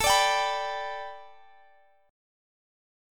Listen to A7sus2sus4 strummed